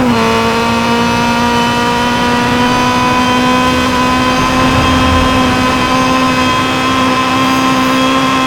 Index of /server/sound/vehicles/lwcars/caterham_r500
fourth_cruise.wav